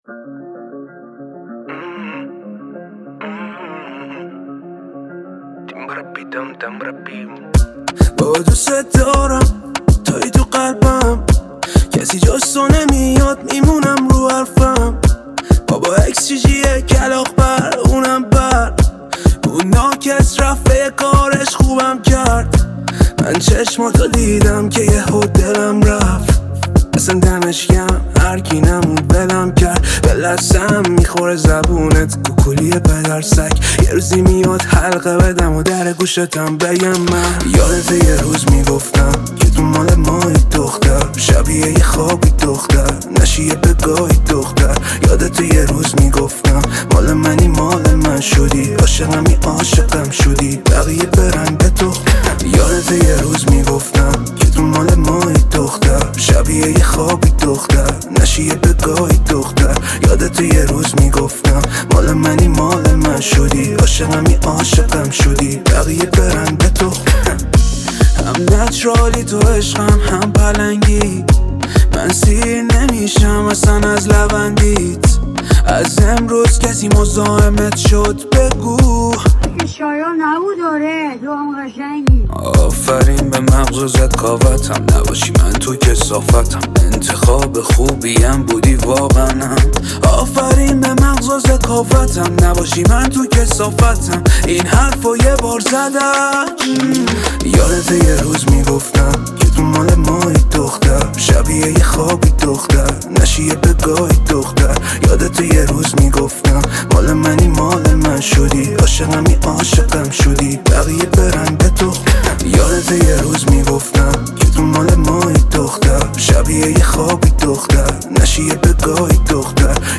رپ
upbeat and fun